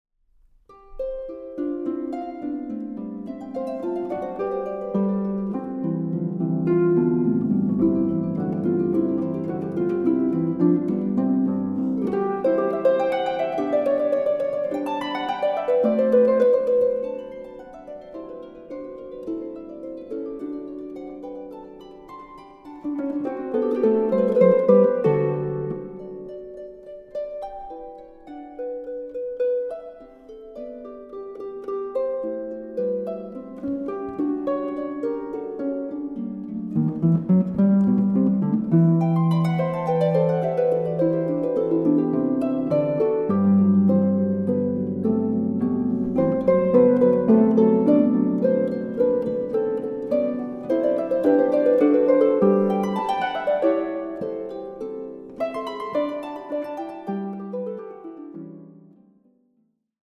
Harfe
Aufnahme: Festeburgkirche Frankfurt, 2024